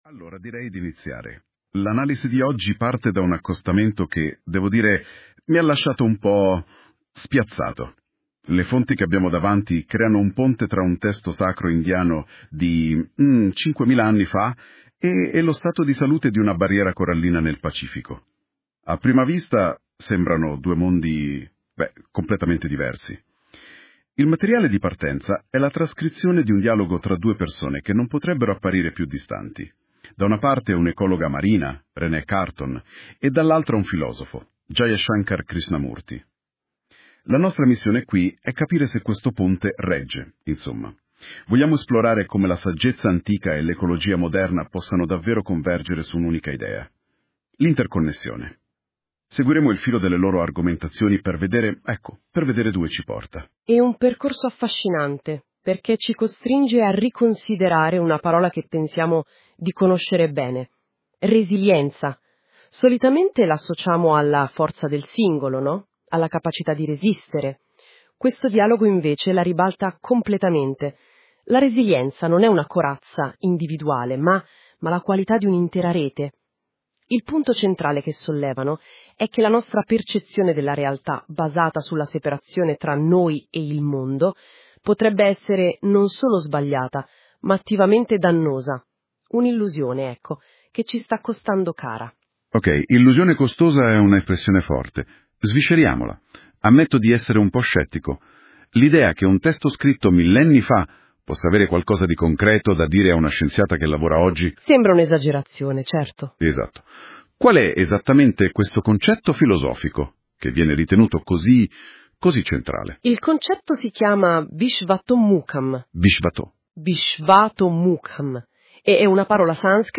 Un dialogo sulla resilienza dei “super-organismi” e lo Spirito dai Volti in ogni direzione.